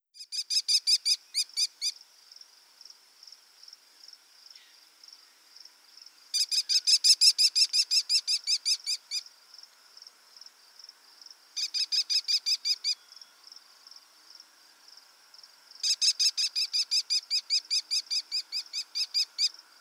Sounds of the Escondido Creek Watershed
American Kestrel